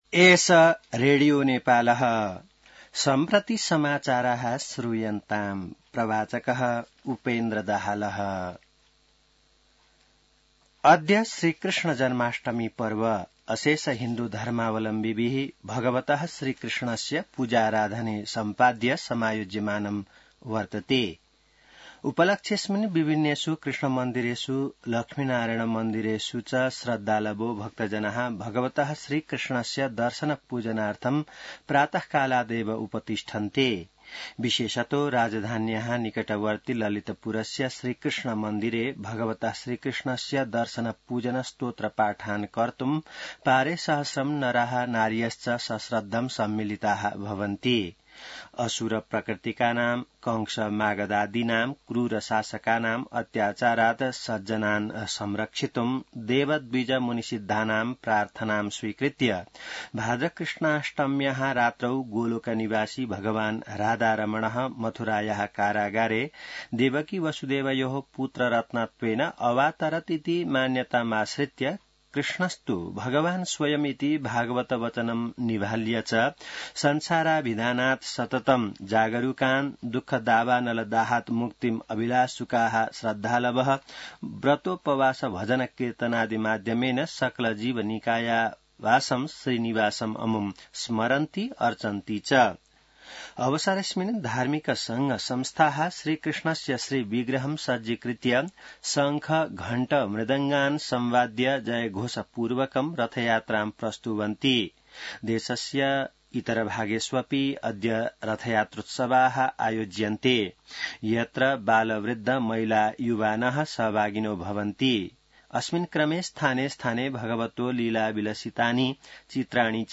संस्कृत समाचार : ३१ साउन , २०८२